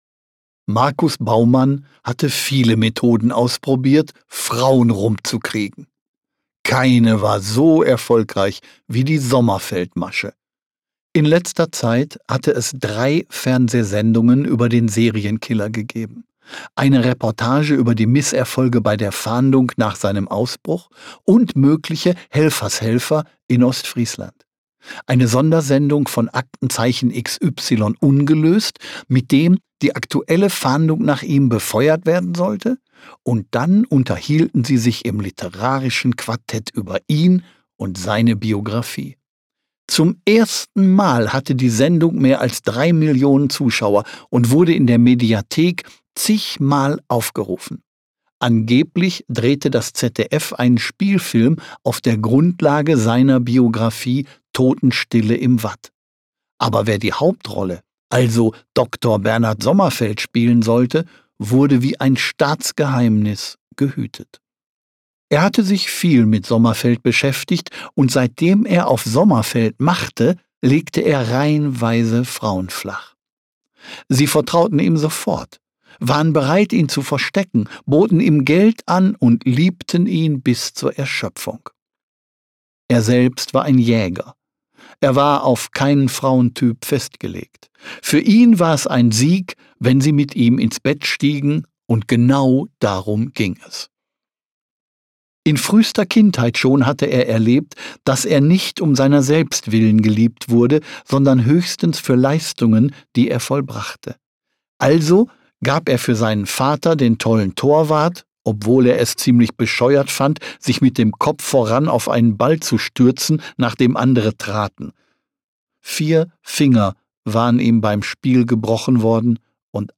Mitarbeit Sprecher: Klaus-Peter Wolf